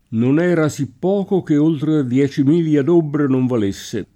dobla [ d 1 bla ] s. f. (numism.)